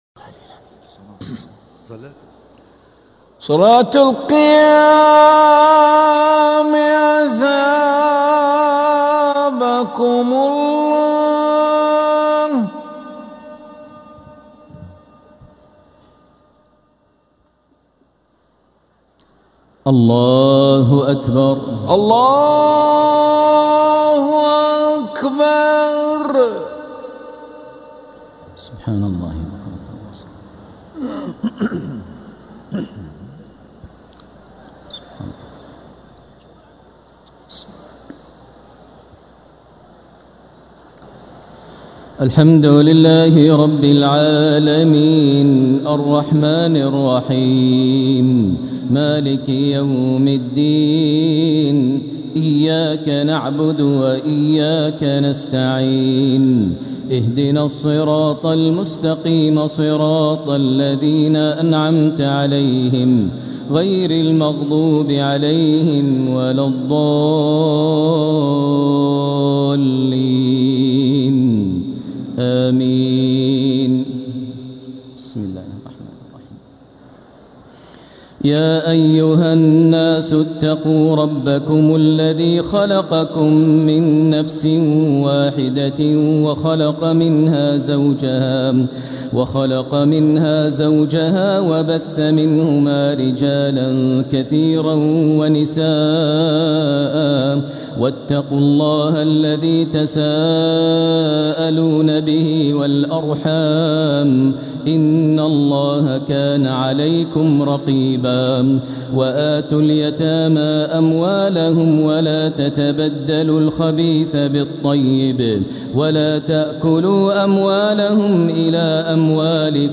تراويح ليلة 6 رمضان 1447هـ فواتح سورة النساء (1-42) | Taraweeh 6th night Ramadan 1447H Surat An-Nisaa > تراويح الحرم المكي عام 1447 🕋 > التراويح - تلاوات الحرمين